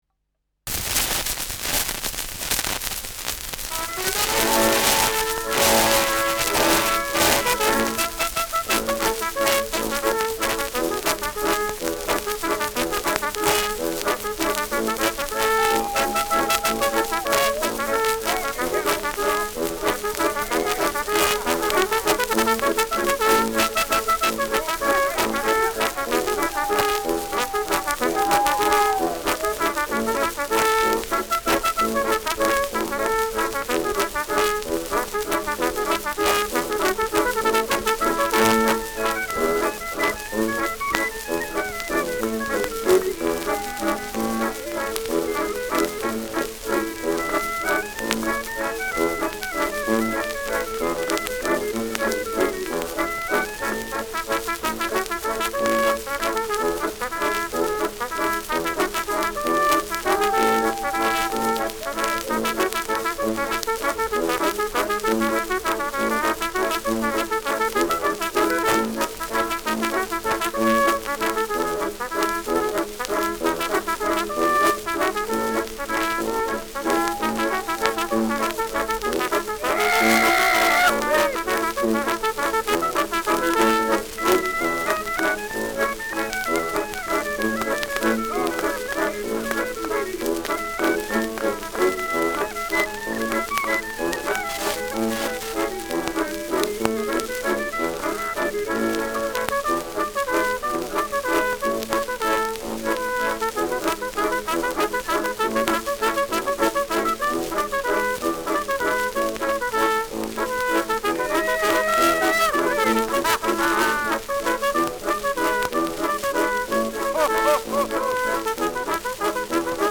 Schellackplatte
Tonnadel springt zu Beginn : präsentes Rauschen : präsentes Knistern : abgespielt : „Schnarren“ : leiert
Dachauer Bauernkapelle (Interpretation)
Mit Juchzern und Zwischenrufen. Die mit 78 rpm abgespielte wurde wahrscheinlich in einem langsameren Tempo aufgenommen.